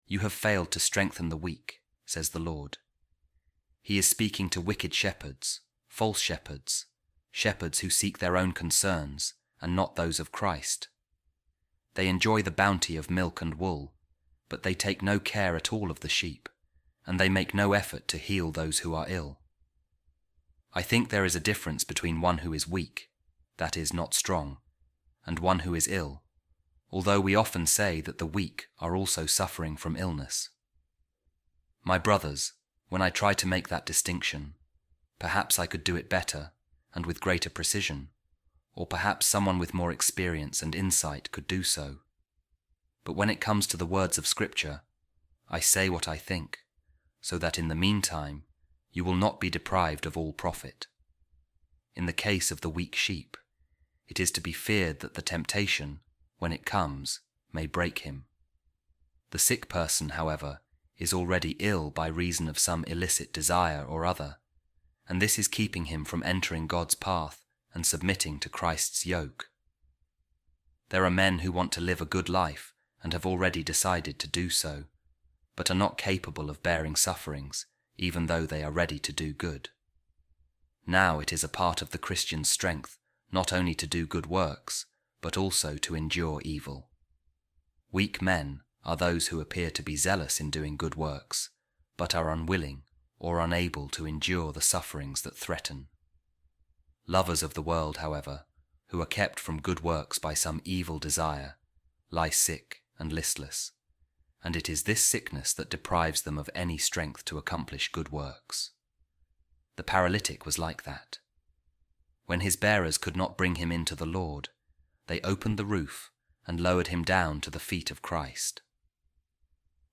Divine Office | Office Of Readings